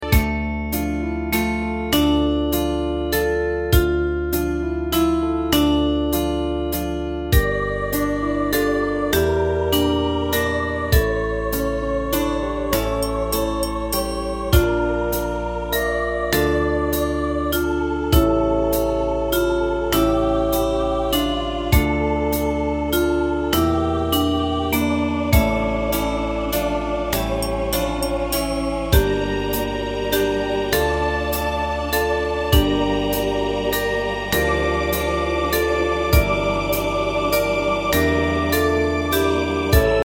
大正琴の「楽譜、練習用の音」データのセットをダウンロードで『すぐに』お届け！
カテゴリー: アンサンブル（合奏） .
歌謡曲・演歌